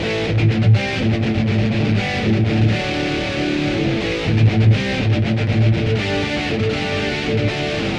描述：简单的电吉他加倍的节奏，轻度失真（Adobe Audition）。四组中的第一组。用廉价的葡萄牙吉布森吉他演奏。
Tag: 120 bpm Rock Loops Guitar Electric Loops 1.35 MB wav Key : Unknown